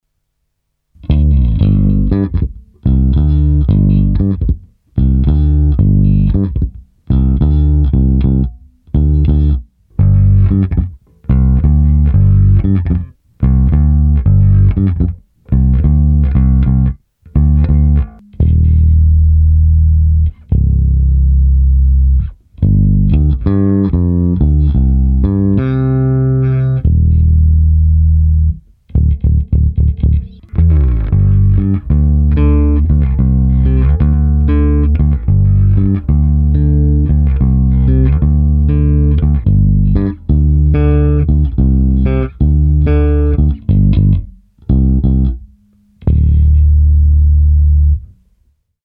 Ukázka tracku basy